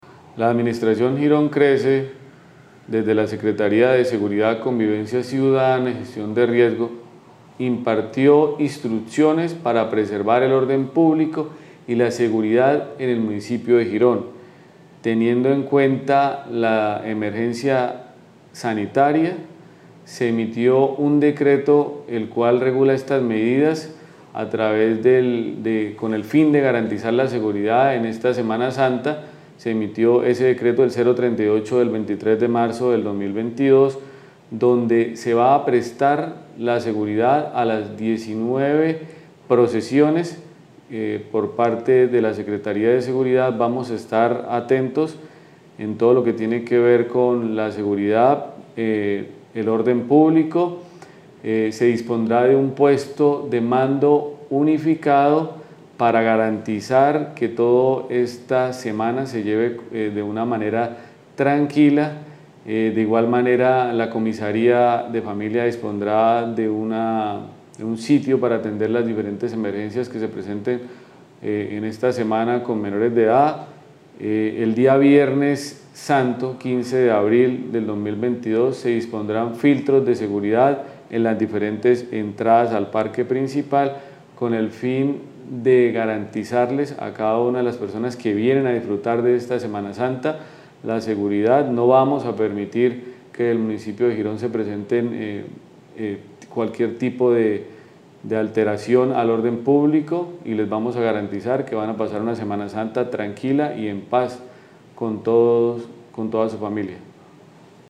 AUDIO CRISTIAN CALDERÓN SEC SEGURIDAD- SEMANA SANTA.mp3